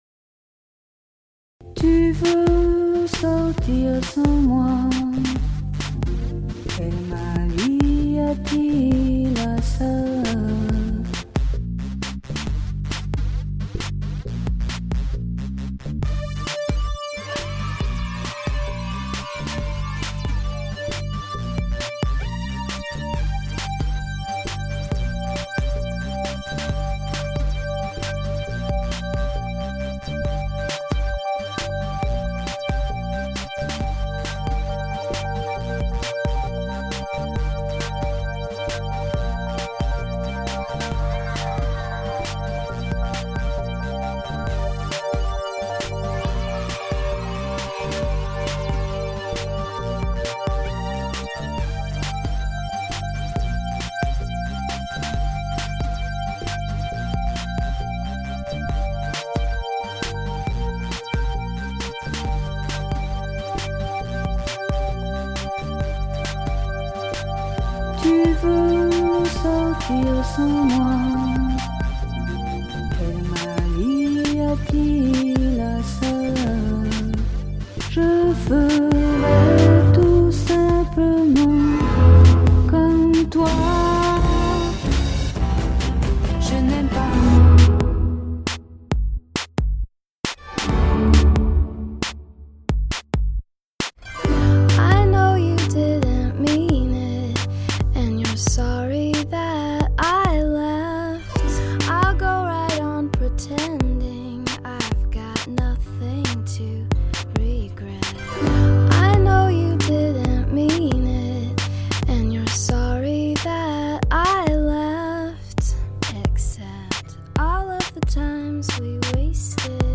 Starts out in G minor, I believe.
First sampled part is Francoise Hardy's "Quel mal y a-t-il a ca".
At some point Petra Haden says, "one". Finally, the harp and singing at the end are from "We're Both So Sorry" by Mirah.
I'd like to change the drums.